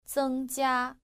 • zēngjiā